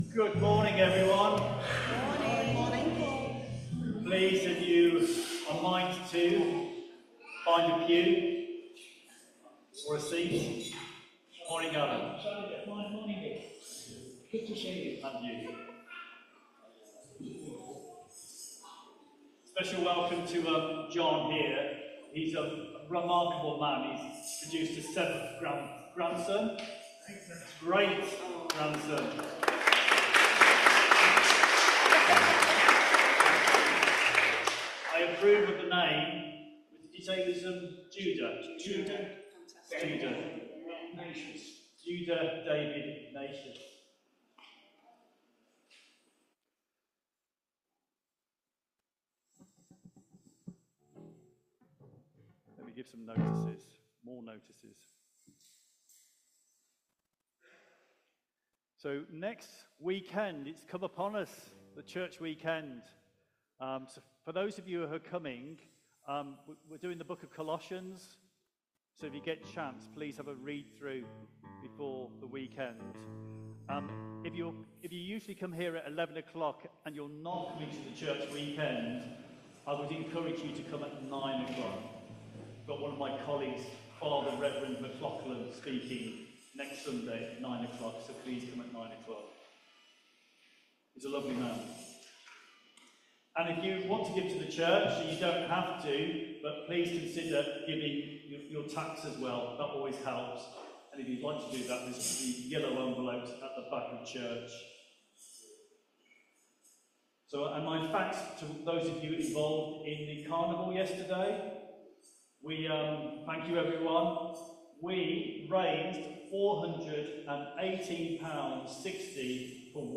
Media for Contemporary Worship on Sun 22nd Jun 2025 11:00 Speaker